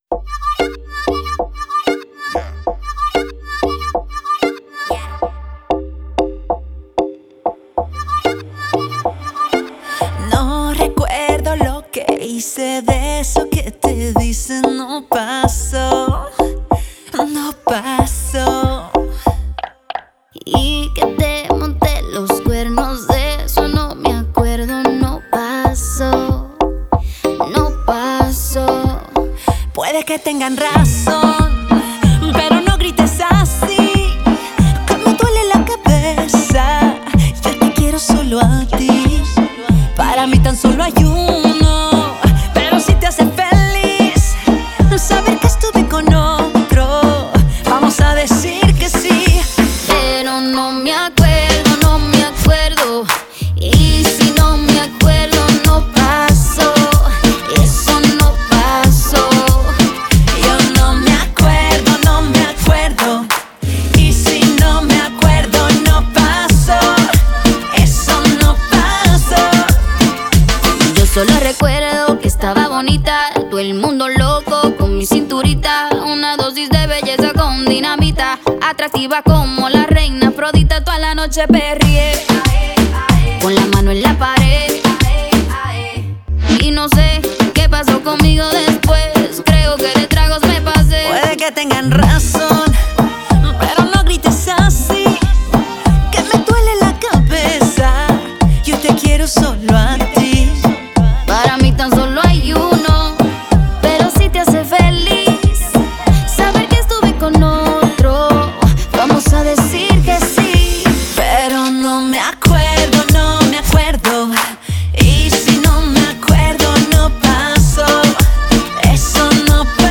دانلود آهنگ سبک لاتین
Latin Music